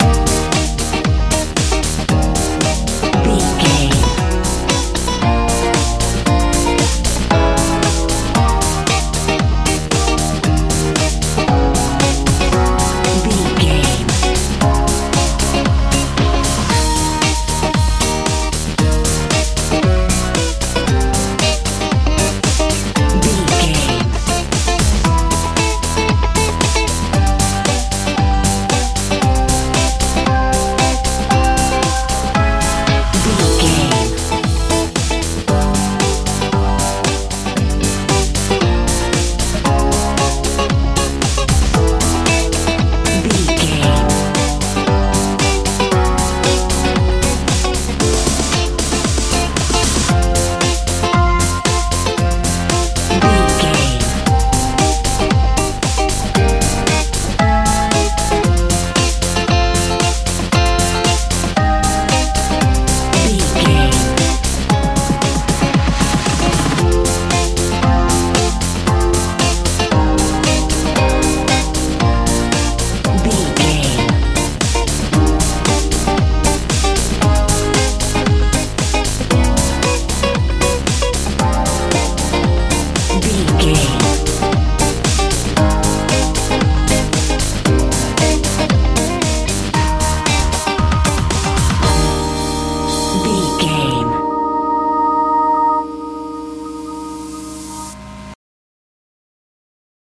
disco feeling music
Ionian/Major
G♯
energetic
bouncy
organ
synthesiser
bass guitar
drums
driving
cheerful/happy
optimistic